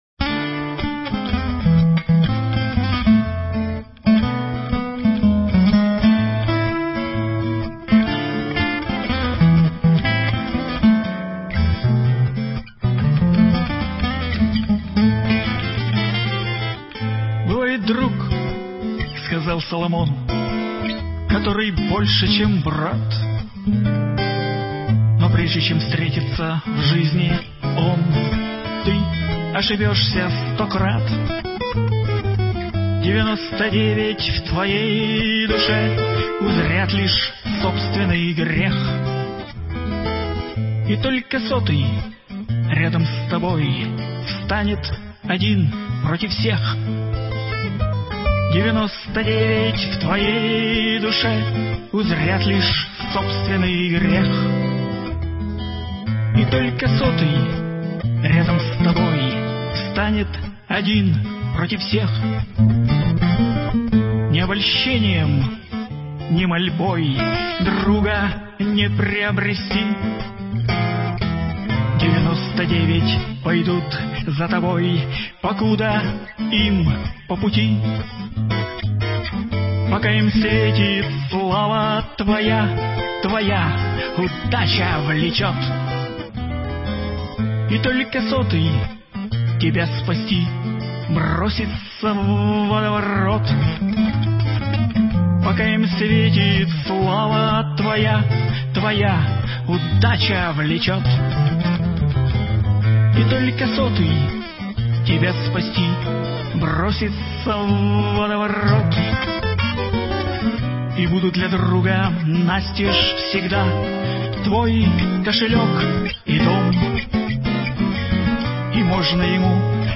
Песня
«бардовской»